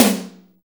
ODD TOM HI.wav